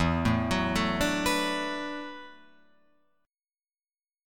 E7sus2sus4 chord